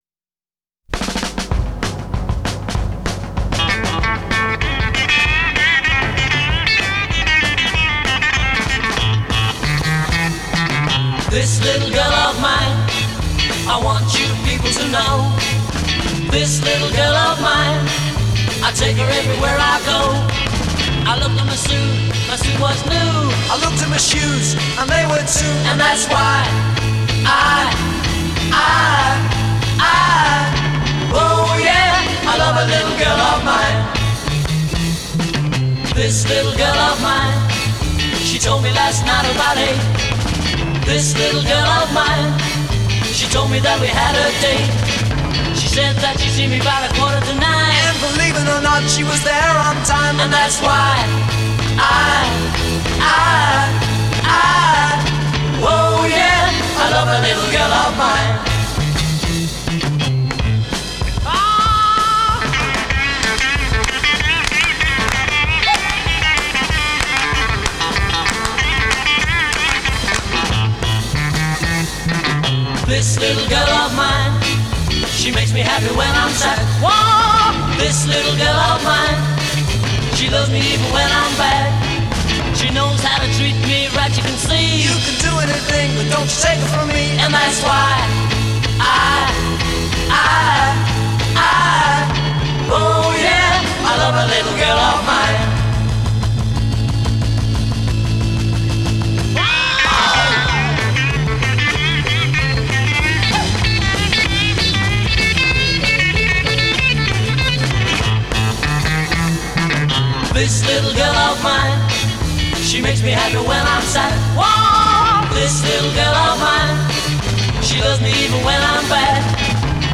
Genre: Pop, Rock & Roll, Beat